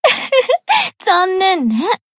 贡献 ） 分类:蔚蓝档案语音 协议:Copyright 您不可以覆盖此文件。
BA_V_Aru_Battle_Defense_1.ogg